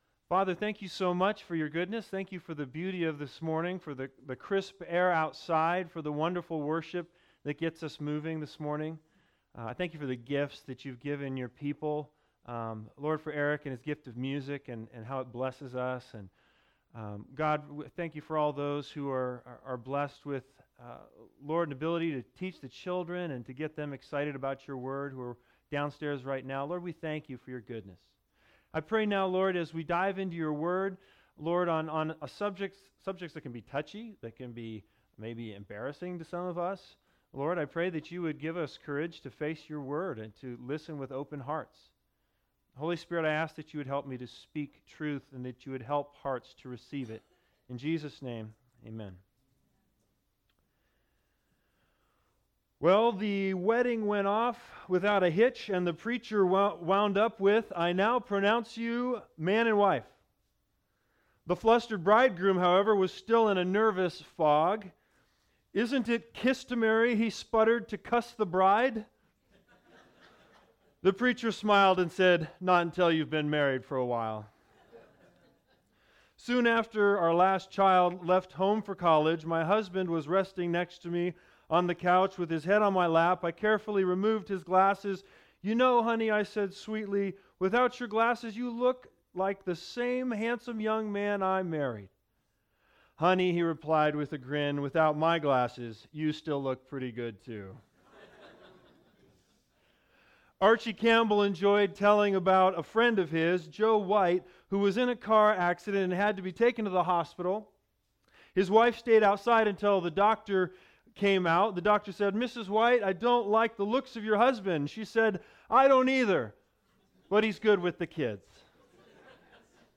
The Big Idea: Marriage is a gift from God! In this sermon, learn 3 keys to understanding how marriage is a gift from God.